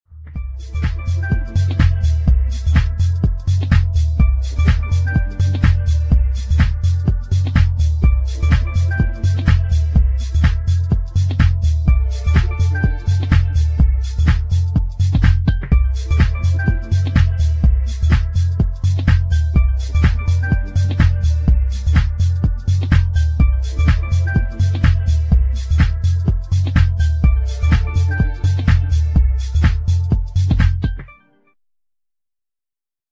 2000 harmonic medium instr.